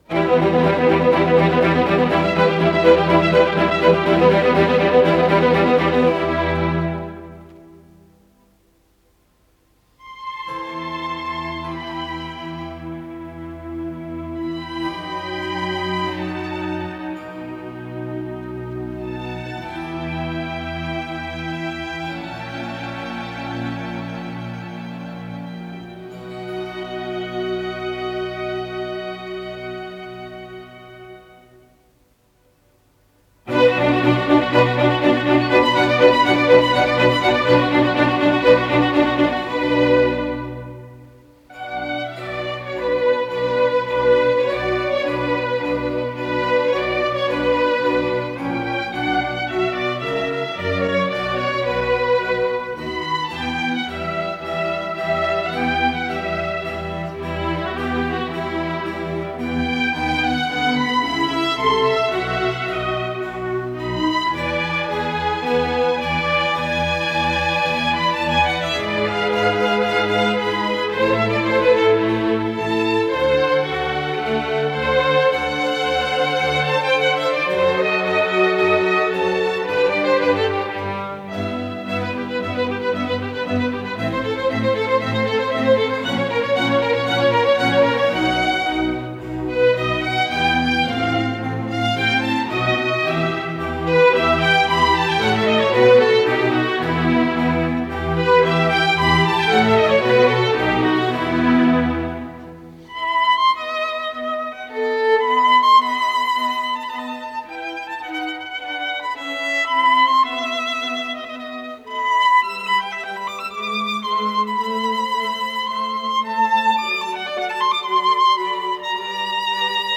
с профессиональной магнитной ленты
Ми минор
ИсполнителиОлег Каган - скрипка
Ансамбль солистов Академического симфонического оркестра Московской государственной филармонии
ВариантДубль моно